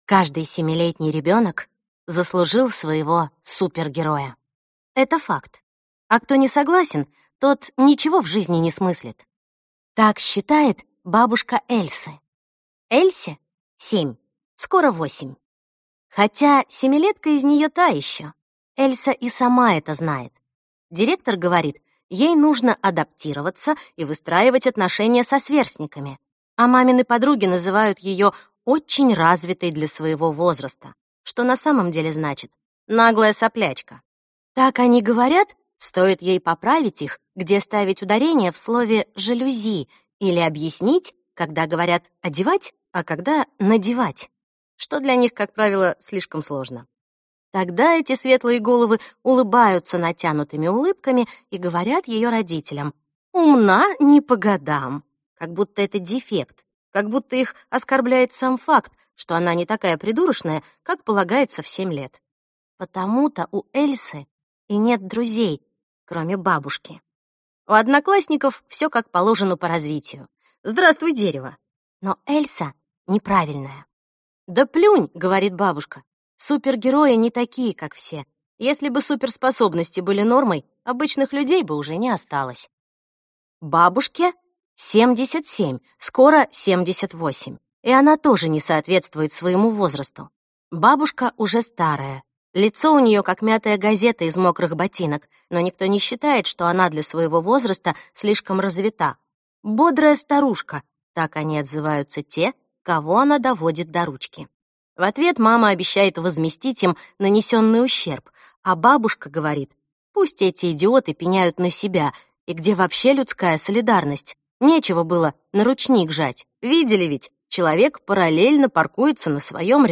Аудиокнига Бабушка велела кланяться и передать, что просит прощения - купить, скачать и слушать онлайн | КнигоПоиск